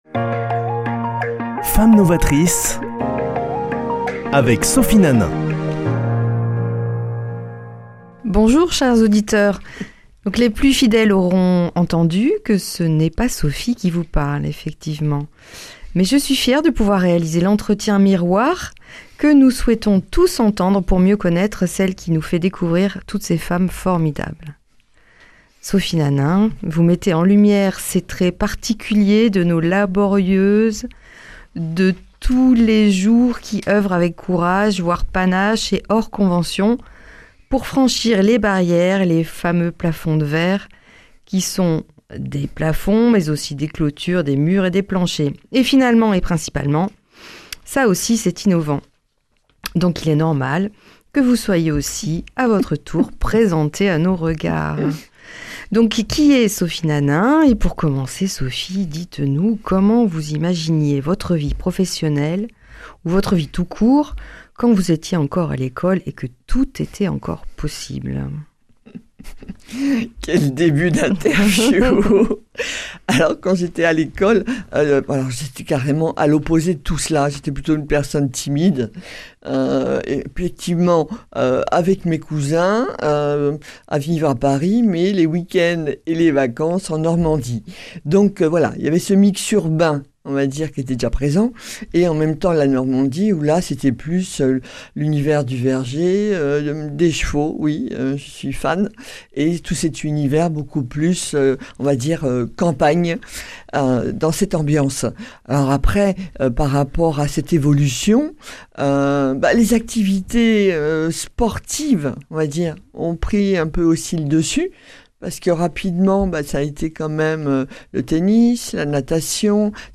Chronique Femmes Novatrices